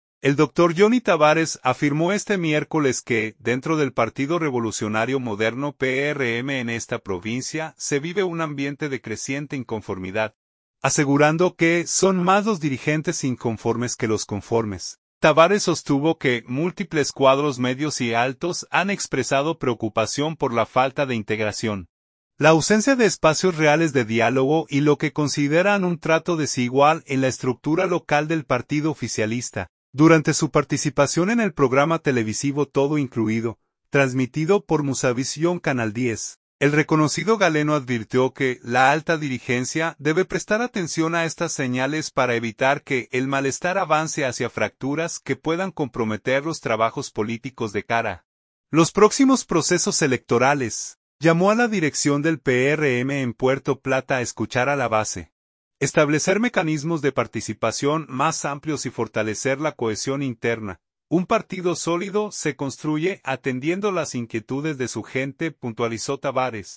Durante su participación en el programa televisivo Todo Incluido, transmitido por Musavisión canal 10, el reconocido galeno advirtió que la alta dirigencia debe prestar atención a estas señales para evitar que el malestar avance hacia fracturas que puedan comprometer los trabajos políticos de cara a los próximos procesos electorales.